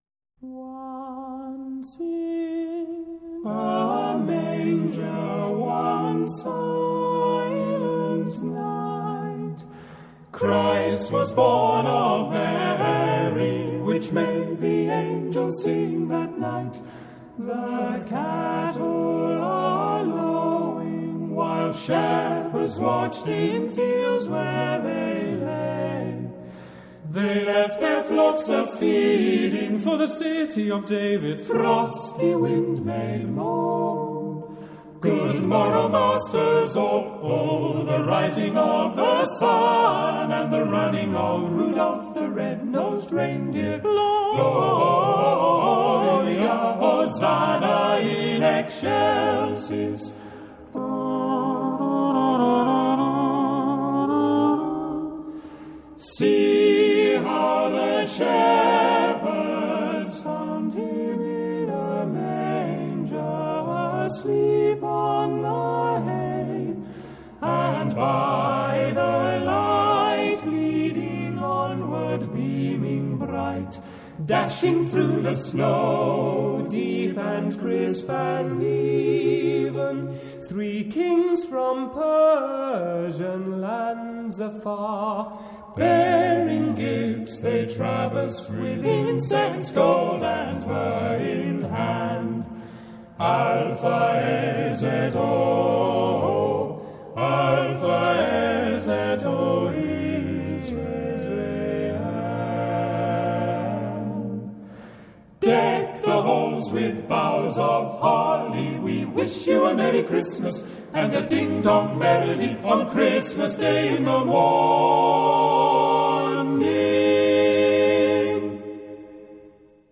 Genre-Style-Forme : Sacré ; Profane ; Carol ; Pot-pourri
Type de choeur : SATB  (4 voix mixtes )
Tonalité : diverses